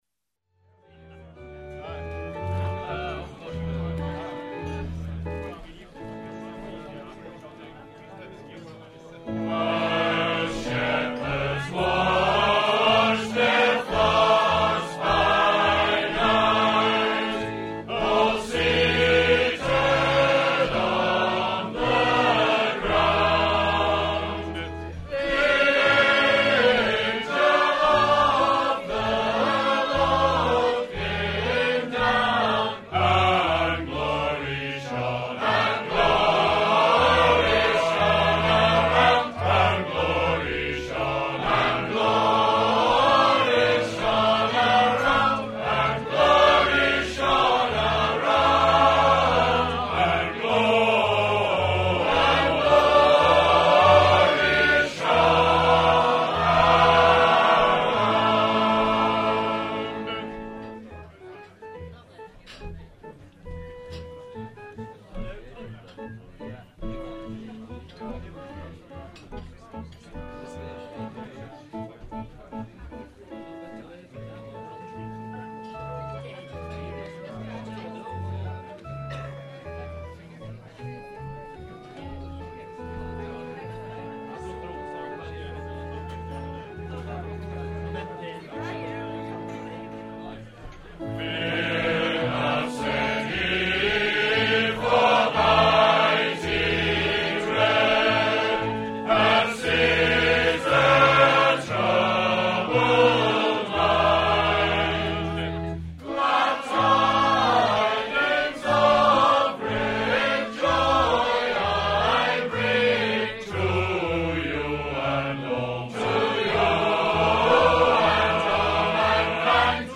In fuguing tunes the last line, lines or phrase of the verse are repeated a number of times with staggered and interlocking parts.
at the Royal Hotel, Dungworth
A Major
Introduction; verse and symphony three times; finishes at end of final verse (no last symphony) with marked rallentando.
Loose and variable performance of aurally remembered note values
Verse 2 sung in c. 49 seconds. That is c. 112 beats per minute; the speed of the other verses varies between c. 108 and 115 bpm.
Each takes about 30 seconds, giving an average speed of 144 bpm - but there is considerable rubato in the performance including a rallentando at the end of the symphony to come back to the verse tempo.
Contrast between instrumental symphony and sung verses; all verses sung in a projected, full voiced way.
Chest voices; male voices predominant, with females singing tune at male pitch; vernacular song style
Basically two parts with some elaboration
Solo electronic organ
Faster tempo than verses with no dynamic contrast within symphony; considerable variation in note length values as the organist plays with the musical material[69]
Dungworth.mp3